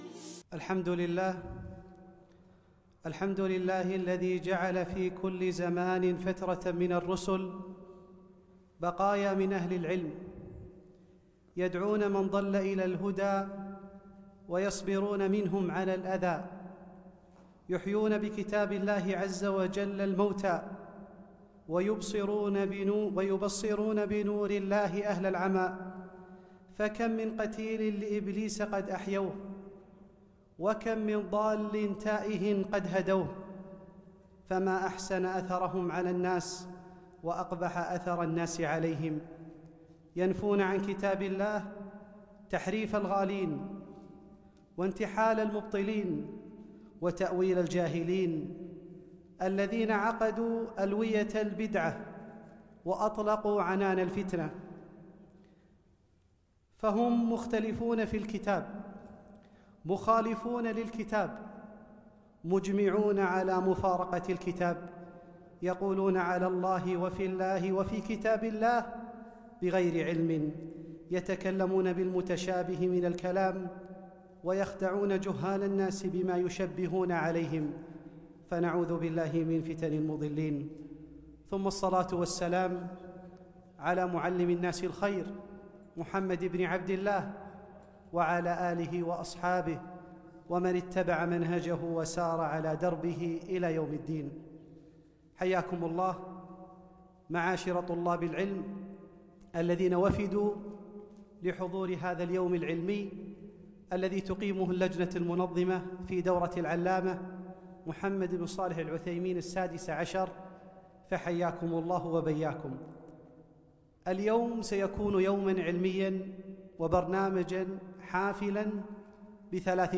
محاضرة - الطريق إلى ضبط الفقه و إتقانه